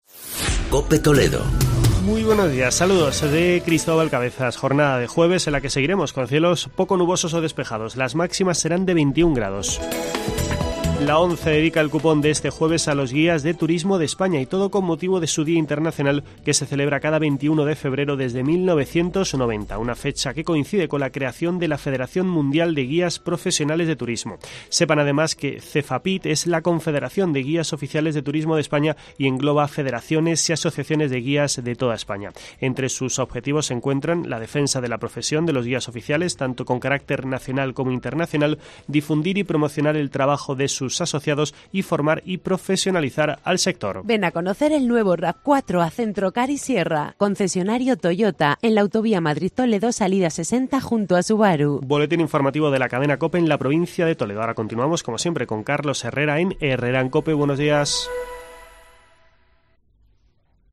Boletín informativo de la Cadena COPE.